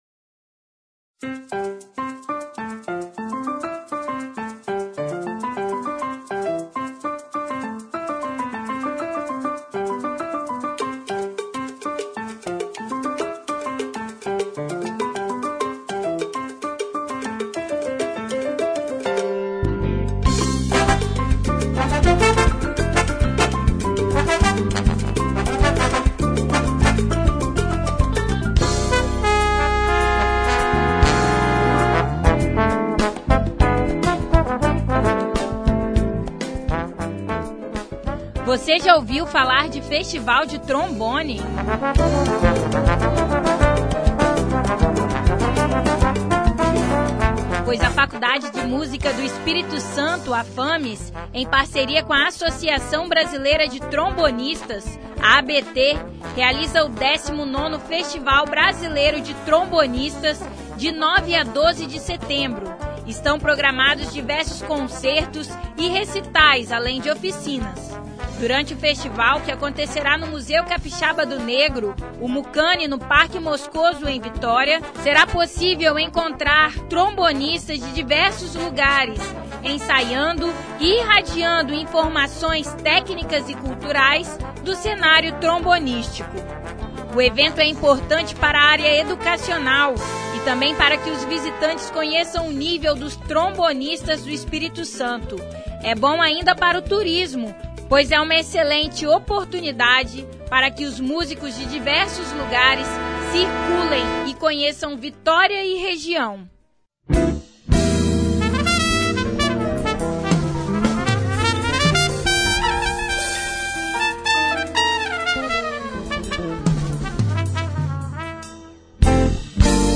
Saiba mais sobre o evento que conta com a participação de artistas como Zé da Velha, Michael Davis e bandas como a Trombonada e Brasilidade Geral, na reportagem que foi ao ar no Programa Revista Universitária.